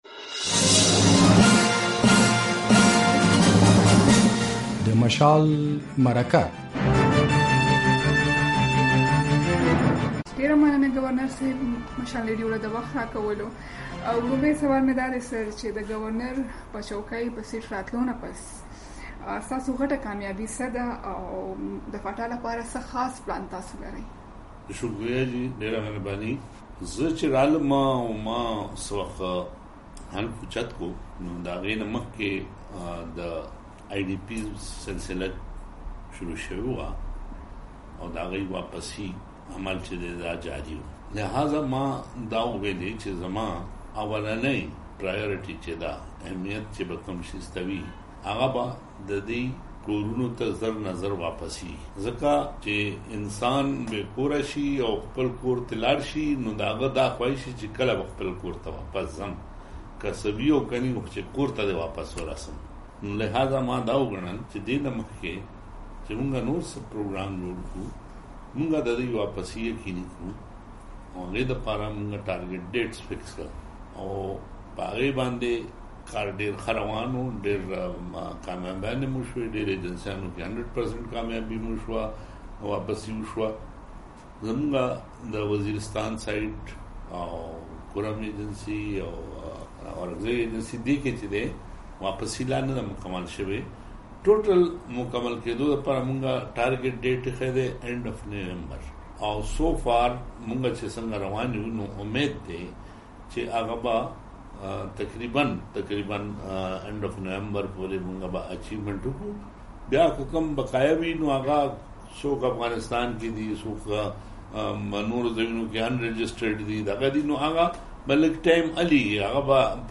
دا اونۍ په مشال مرکه کې د خېبر پښتونخوا ګورنر اقبال ظفر جګړا مو مېلمه دی. نوموړی په دې مرکه کې وايي له قبایلي سیمو د کډه شویو خلکو واپسي یې لومړۍ ترجېح ده او په ډېره کمه موده کې به ټول قبایلي کډوال خپلو سیمو ته ستانه شي. جګړا د قبایلي سیمو د سباوون په اړه هم په دې مرکه کې معلومات راکړي او وايي په راتلونکیو څو کلونو کې به قبایلي سیمې په قومي بهیر کې شاملې شي.